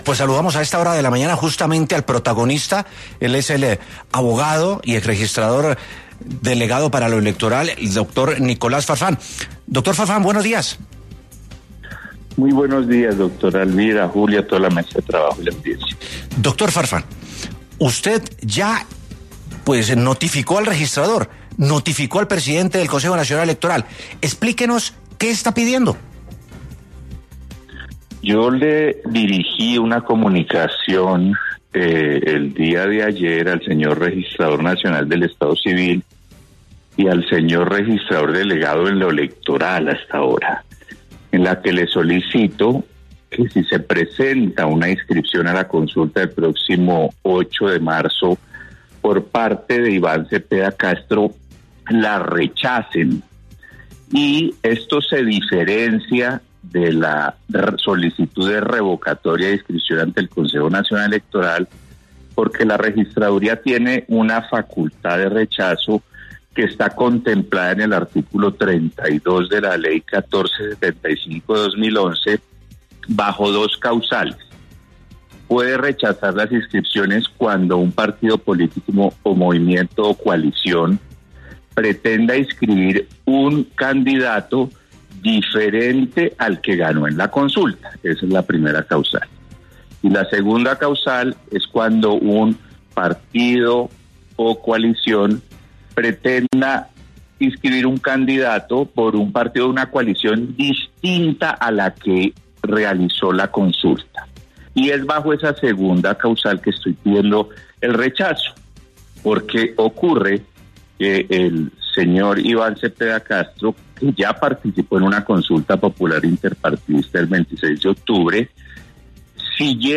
En entrevista con 6AM W de Caracol